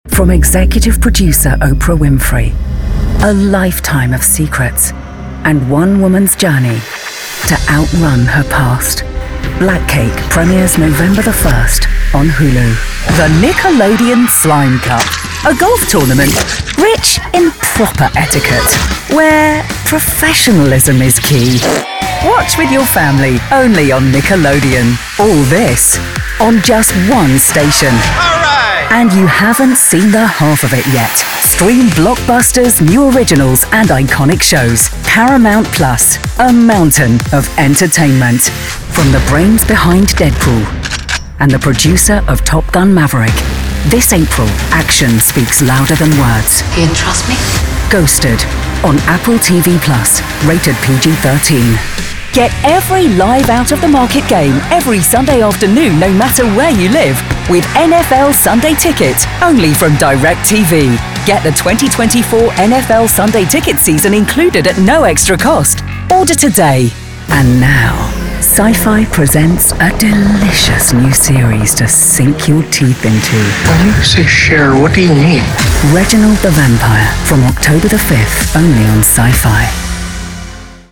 English (British)
Movie Trailers